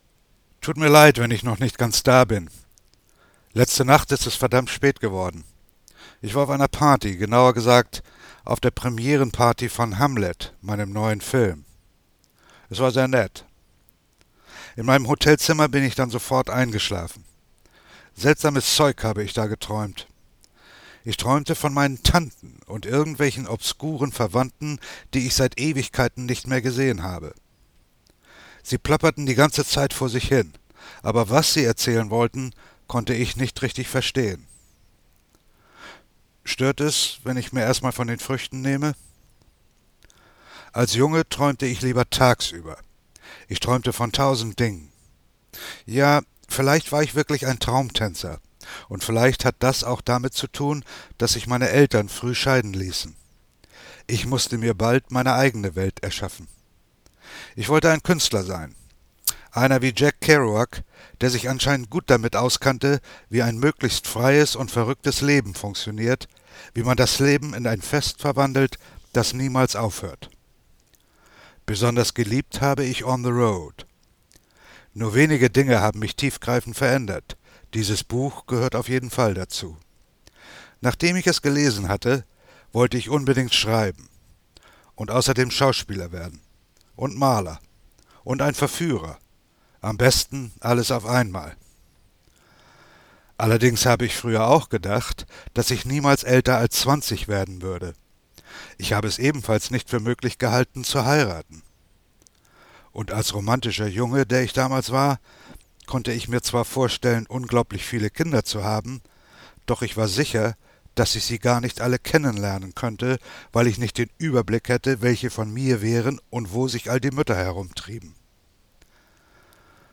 Sprechprobe: Sonstiges (Muttersprache):
Native German speaker; professional, soothing, distinct middle age voice